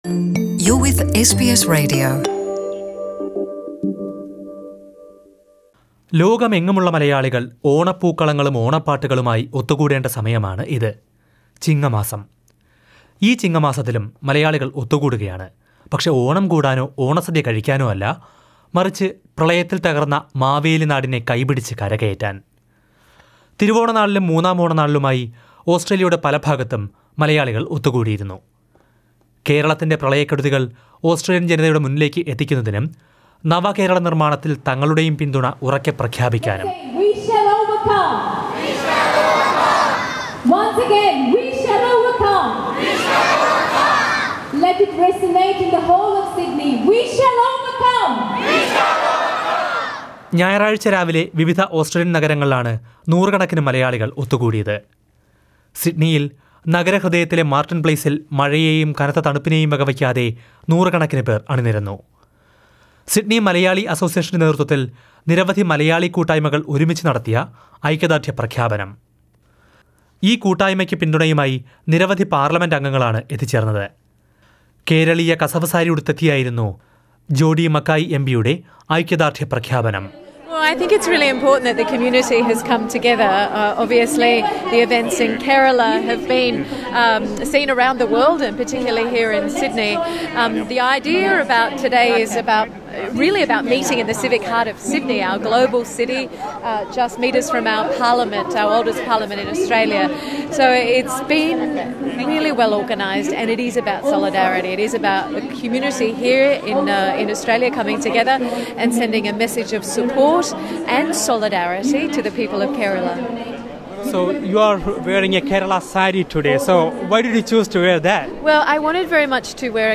Malayalee community in Australia gathered in various cities to express their solidarity with flood-ravaged Kerala. Many politicians including ministers and MPs attended the events and assured their support. Listen to a report.